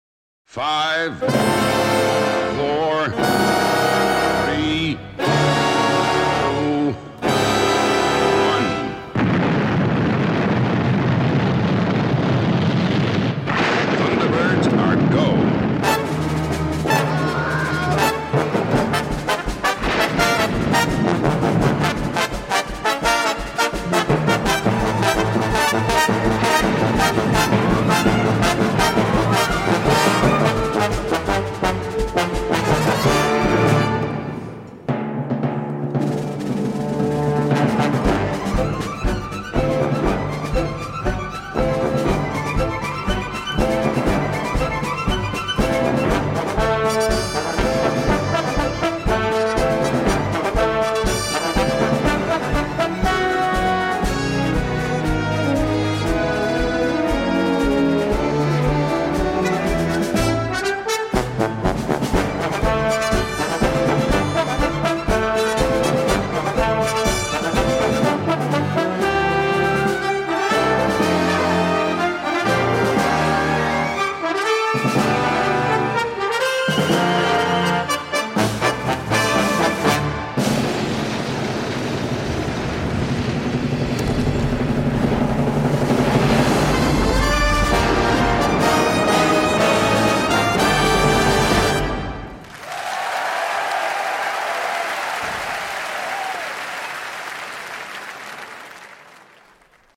Merci au label d’avoir conservé des applaudissements.
Très marrant ce vrai live (ça applaudit, ça chahute !)